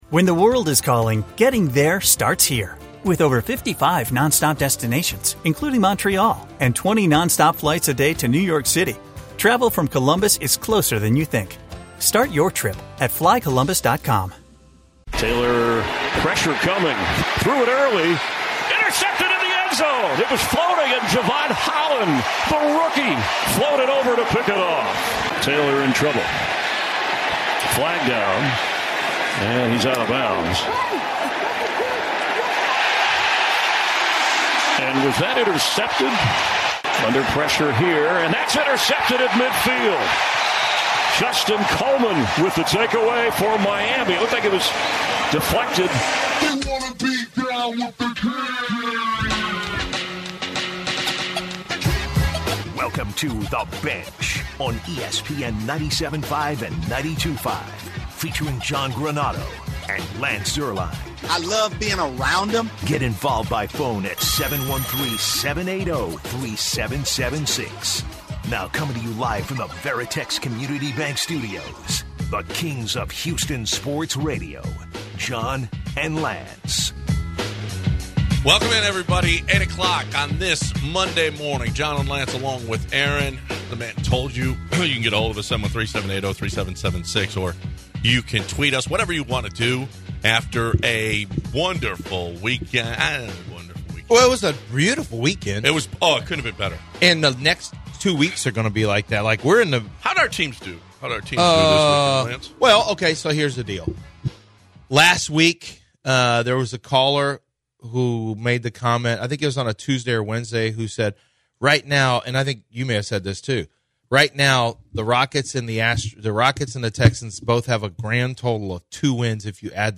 Listeners also chime in with what they learned this weekend.